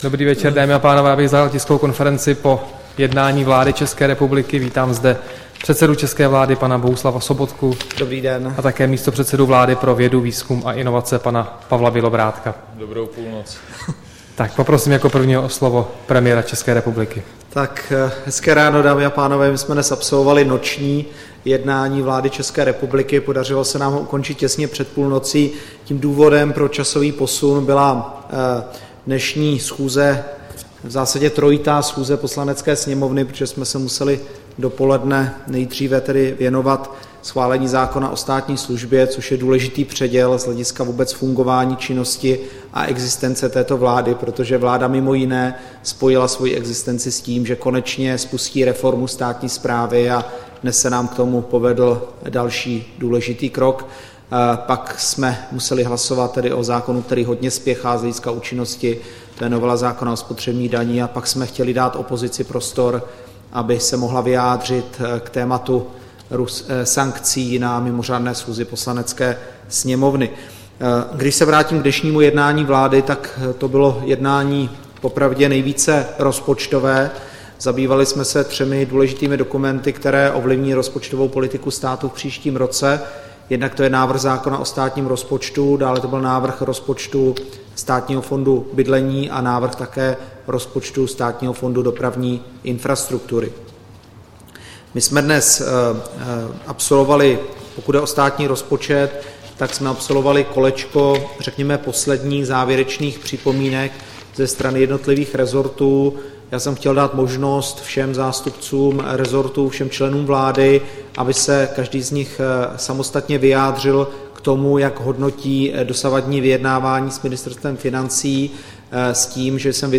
Tisková konference po jednání vlády, 10. září 2014